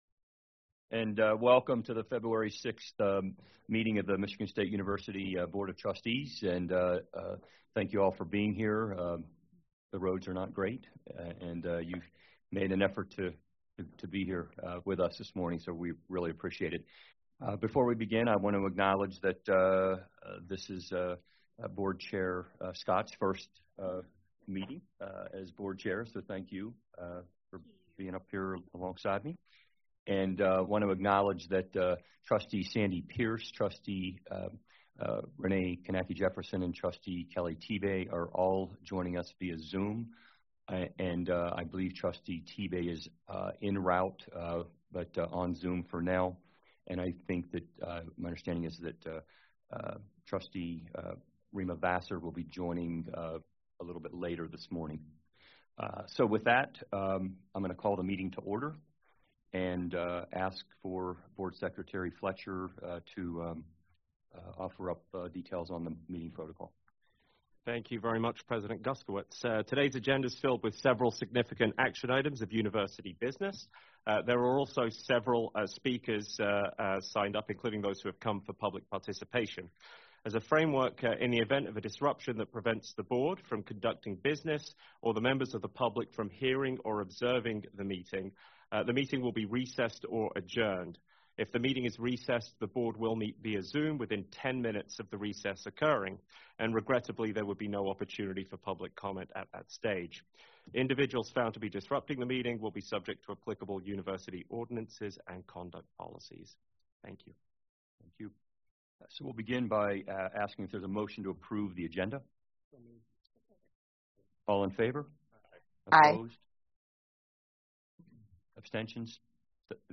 Where: Board Room, 401 Hannah Administration Building